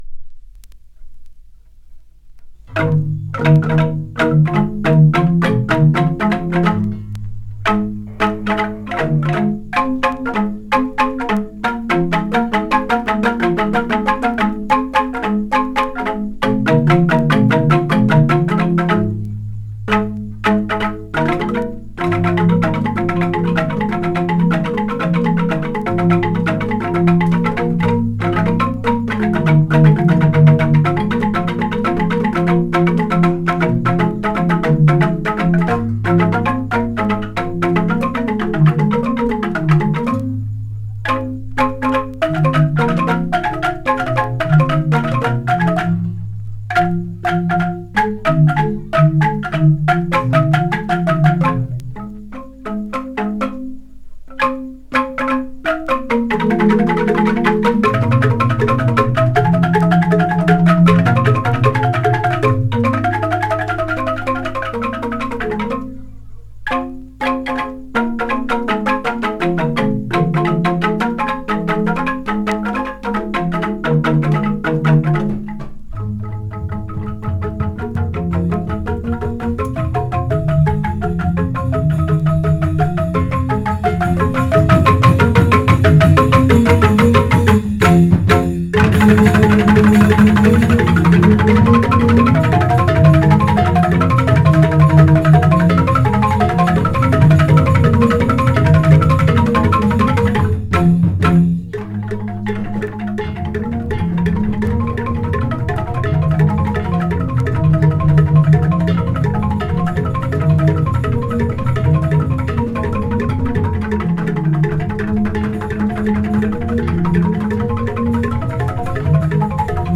Joged Yeh Mekecir / Bali Joged Bumbung 558501 :���� ���㥺 �쥳���� ��� Bamboo Music